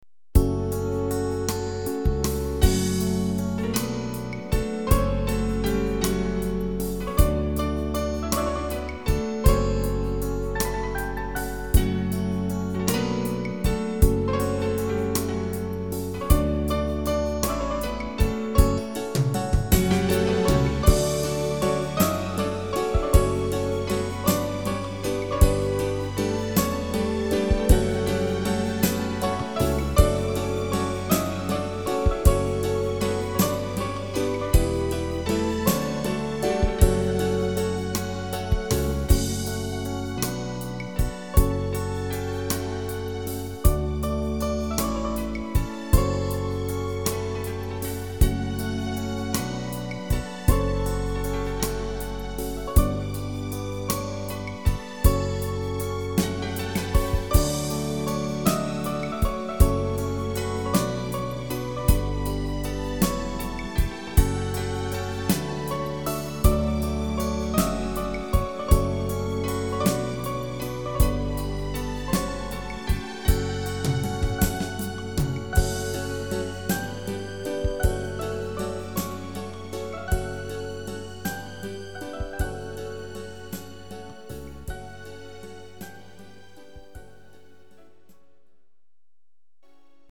6/8 pop